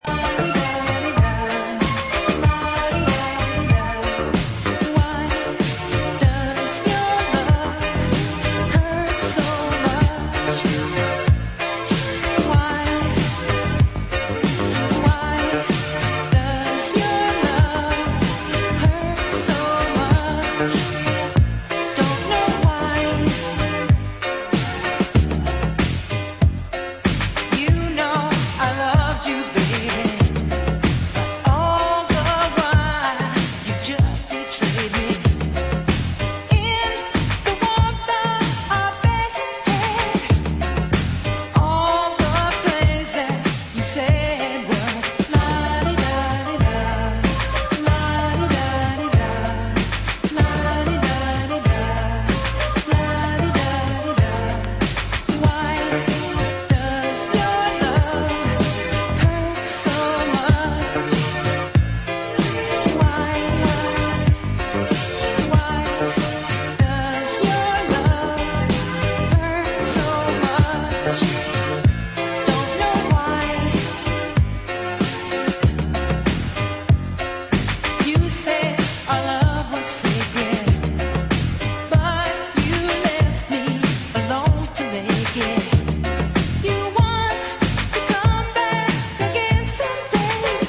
Remastered reissue
extended disco mix
A must-have Balearic disco... more...